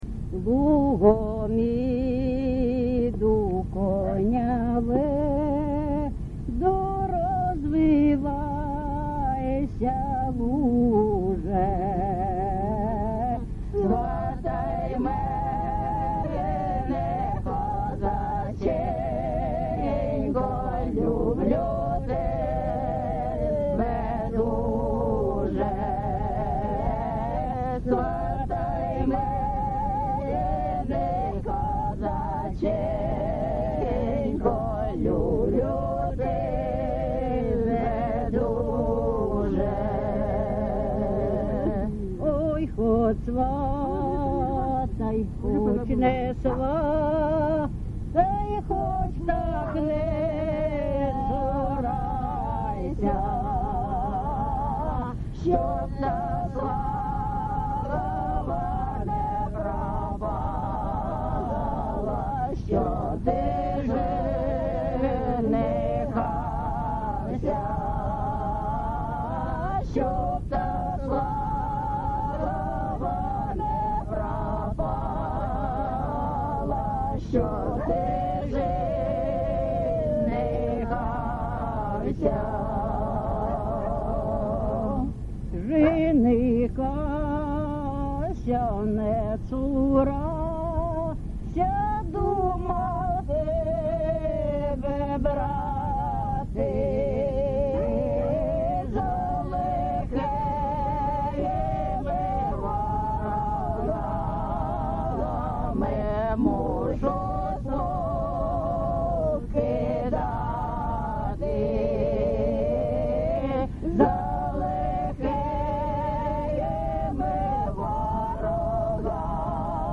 ЖанрПісні з особистого та родинного життя
Місце записус. Богородичне, Словʼянський район, Донецька обл., Україна, Слобожанщина